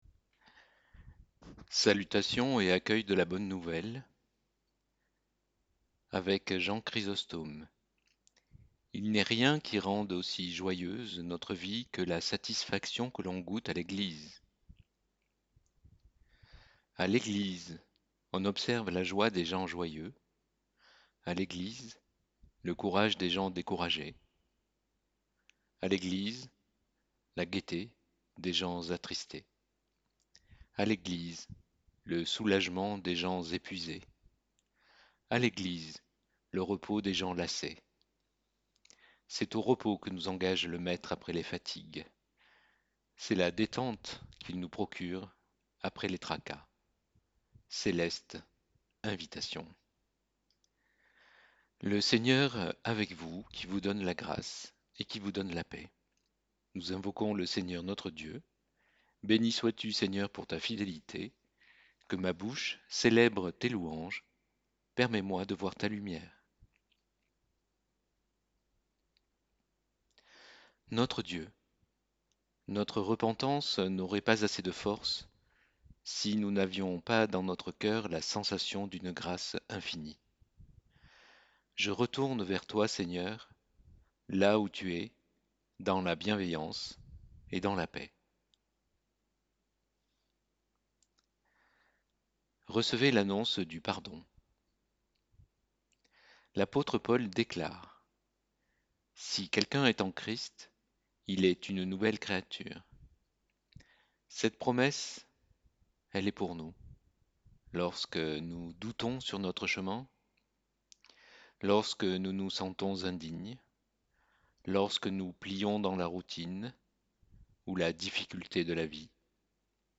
Liturgie et prédication 22 mai 2022.mp3 (29.8 Mo) Liturgie et prédication 22 mai 2022.pdf (80.74 Ko)